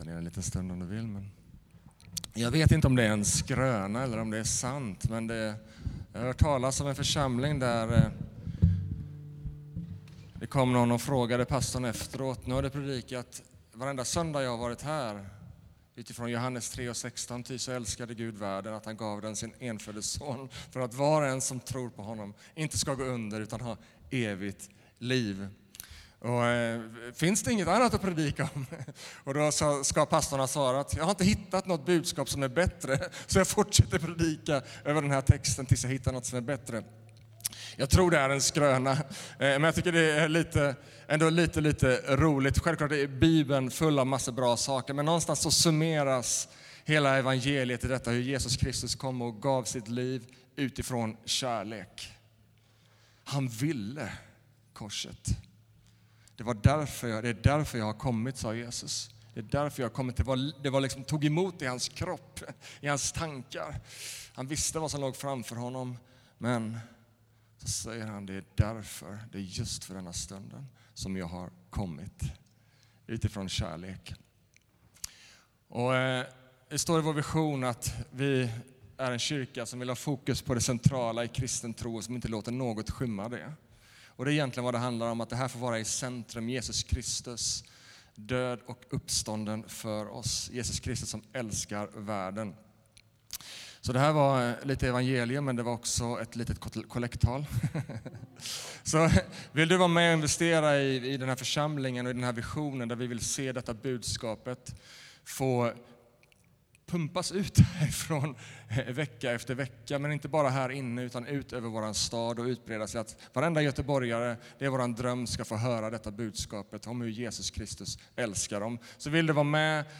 Sermons CENTRO